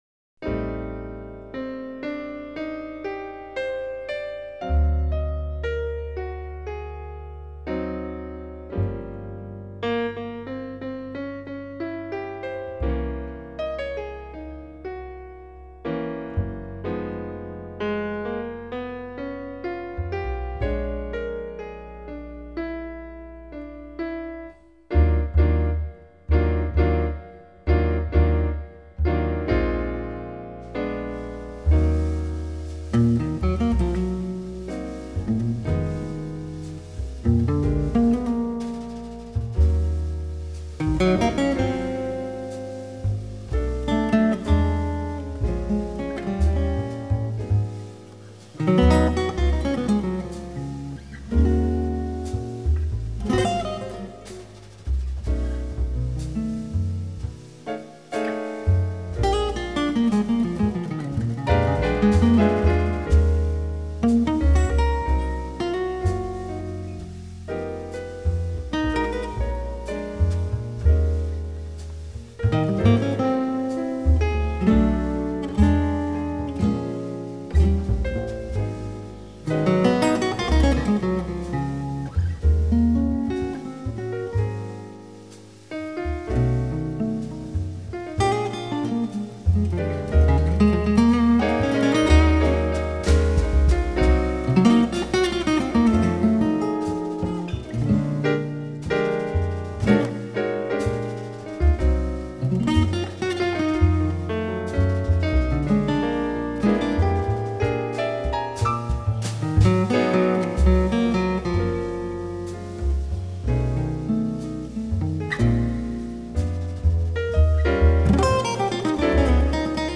Jazz News